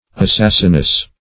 Assassinous \As*sas"sin*ous\